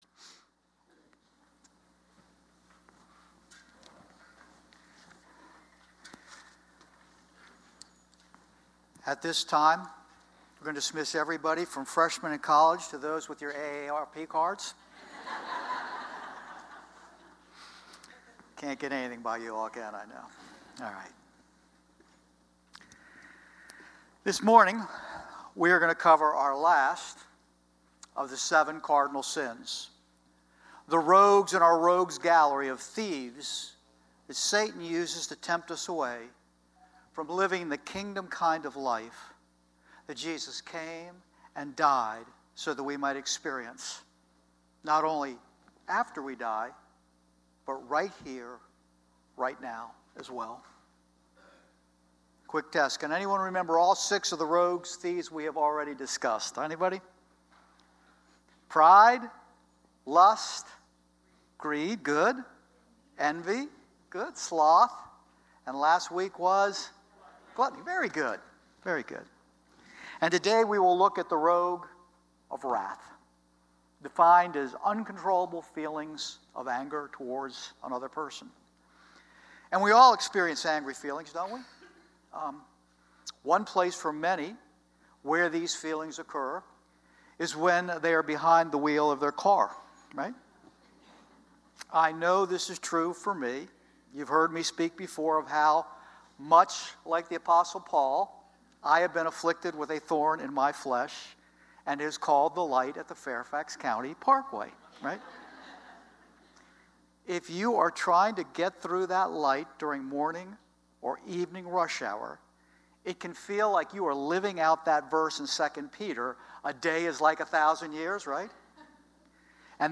10:30 Service Audio Sermon